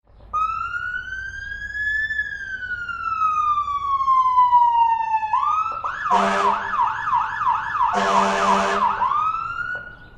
Firetruck Sirene-sound-HIingtone
firetruck-sirene_25020.mp3